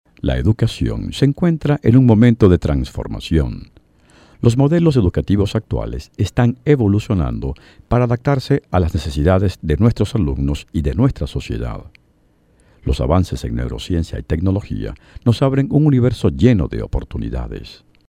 Venezuelan male  voice over